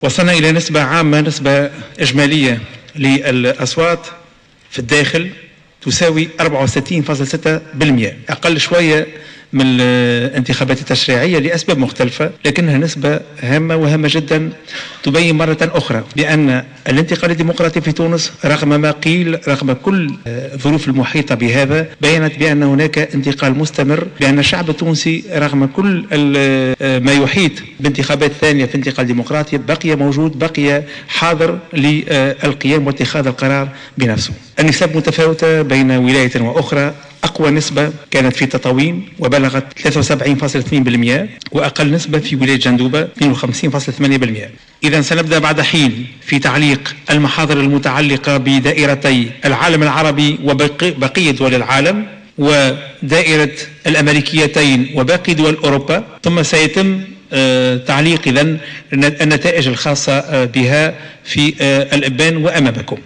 صرح رئيس الهيئة العليا المستقلة للانتخابات شفيق صرصار خلال ندوة صحفية أن نسبة التصويت بالنسبة للانتخابات الرئاسية في الداخل بلغت 60ر64 في المائة.